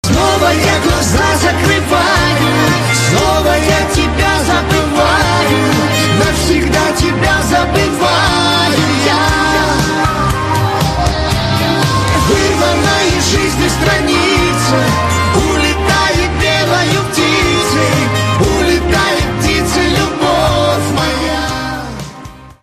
поп
дуэт
эстрадные